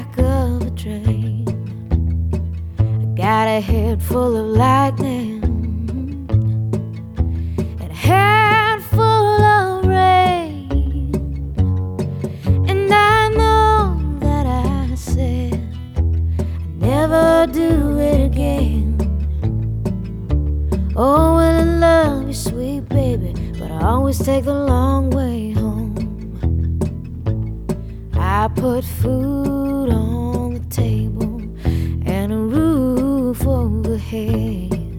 Vocal Jazz
Жанр: Поп музыка / Джаз